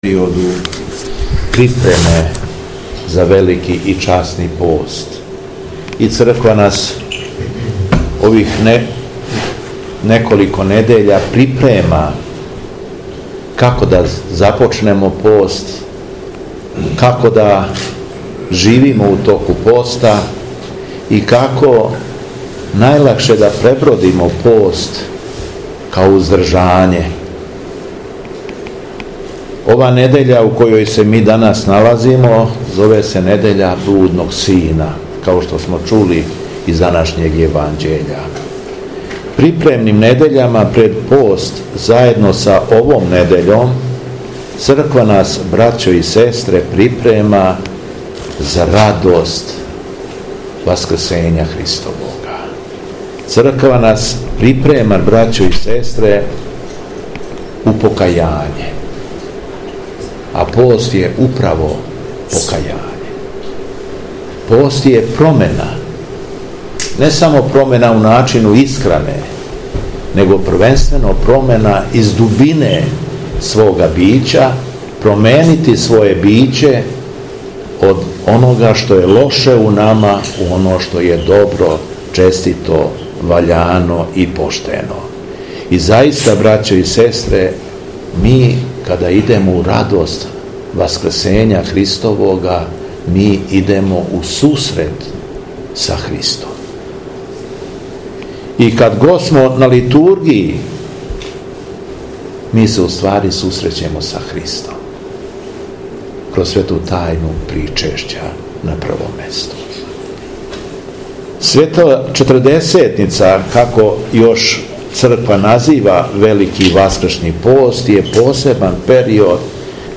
Звучни запис беседе - Покајање је радовање.